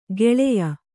♪ geḷeya